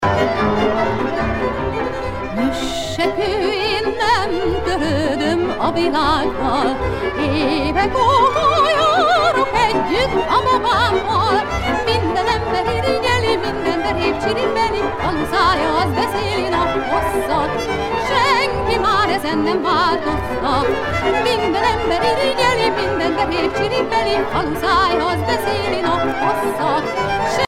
danse : csárdás (Hongrie)
Pièce musicale éditée